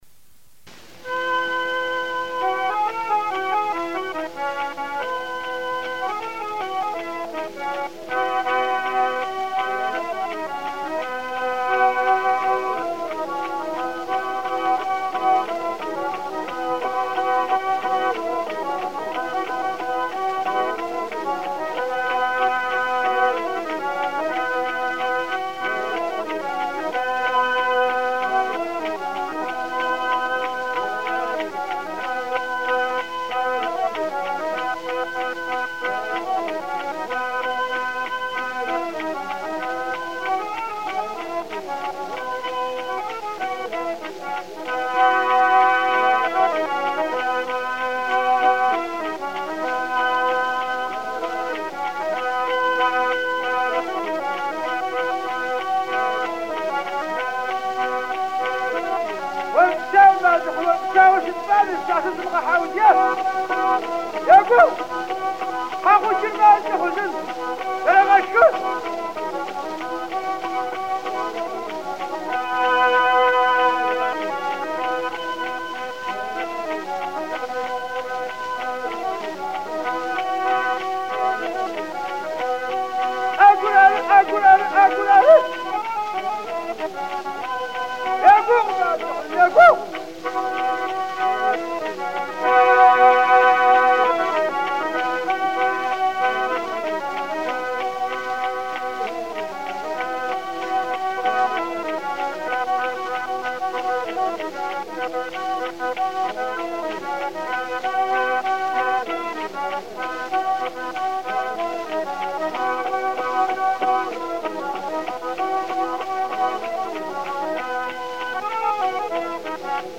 Самая ранняя звукозапись мелодии сандрака была произведена в 1911 году в Армавире английскими звукоинженерами фирмы «Gramophon».
Свою игру музыканты сопровождали хоровым пением жъыу.
Музыкальная композиция сандрака основана на сочетании двух построений — «колен».
Второе «колено» представляет собой отыгрыш, украшающий основную мелодию.
Адыгский наигрыш "Сандрак"
Запись 1911 г. фирмы "Граммофон"